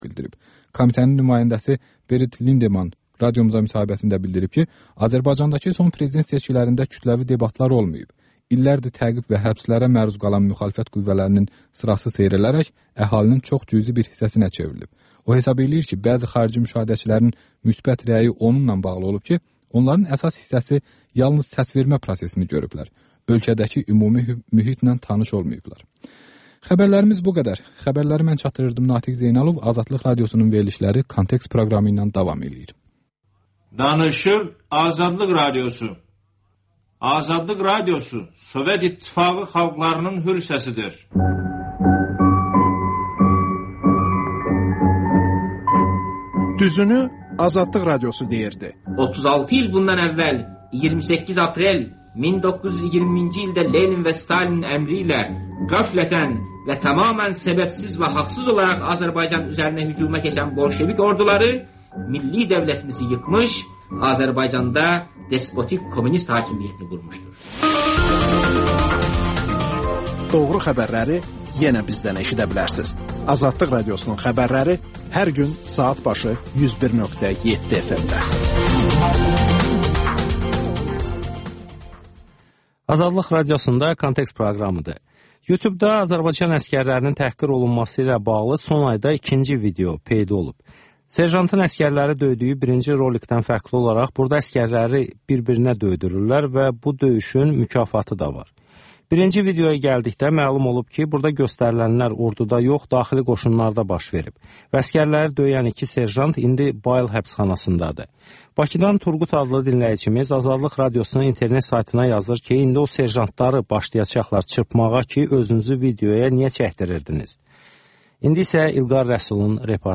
Hadisələrin təhlili, müsahibələr, xüsusi verilişlər.